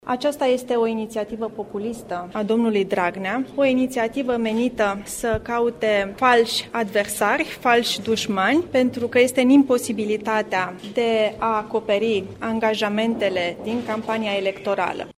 În plus, subiectul dispariţiei a 10 miliarde de lei din buget este întreţinut articial de PSD, a afirmat liderul interimar al PNL, Raluca Turcan: